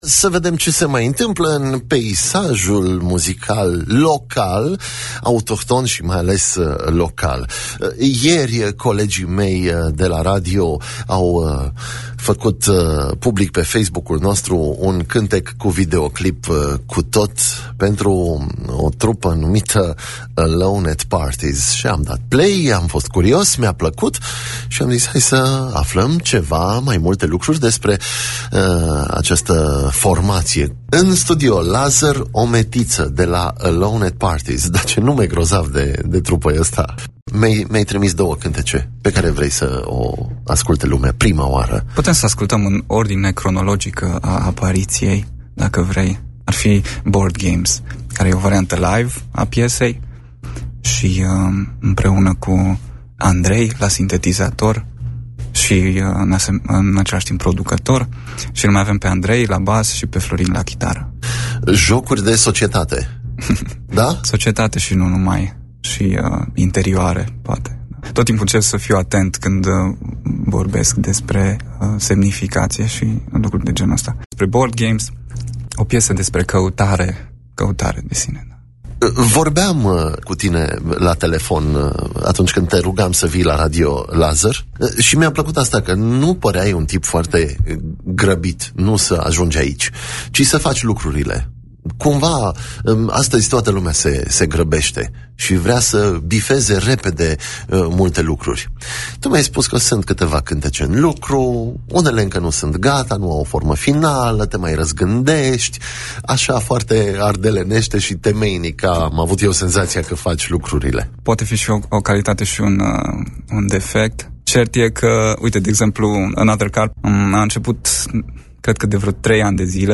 Am scos din fișierul audio piesele difuzate în timpul emisiunii, dar le puteți asculta la linkurile de mai jos: